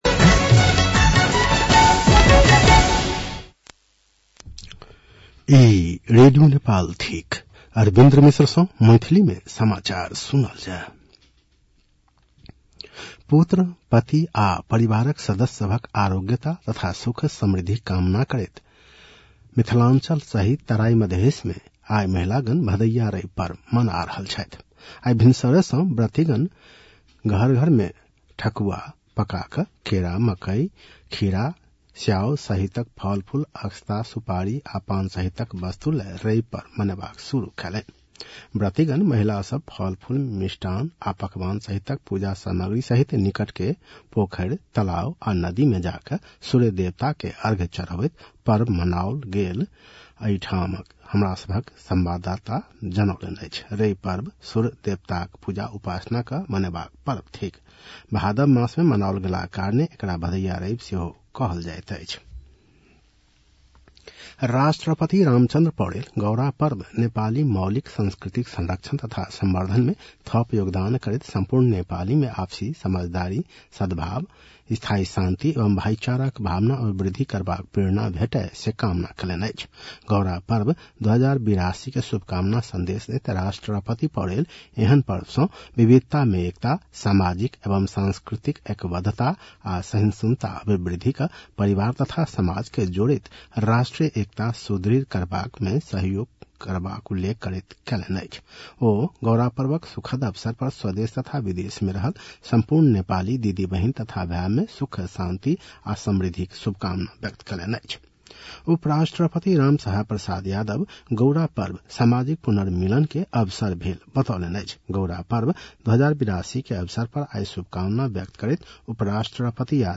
मैथिली भाषामा समाचार : १५ भदौ , २०८२